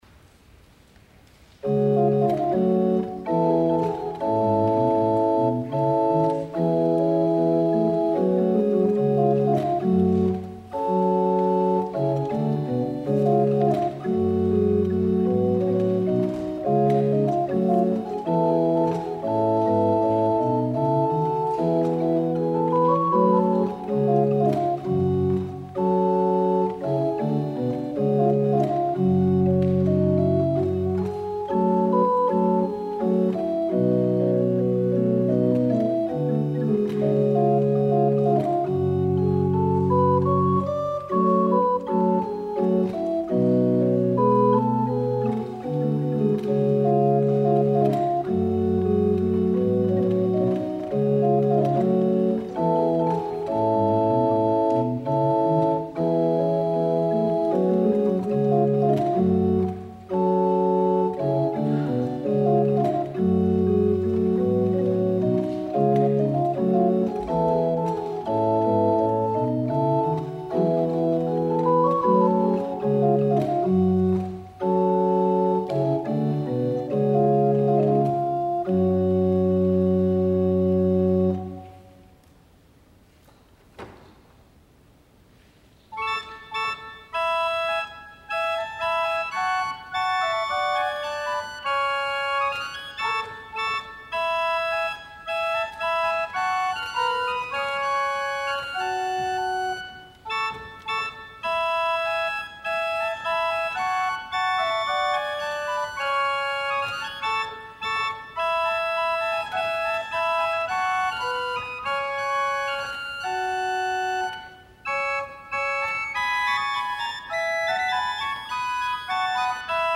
14 Gaillardes 9 Pavennes, 7 Branles et 2 Basses Dances 鍵盤楽器のための舞曲集より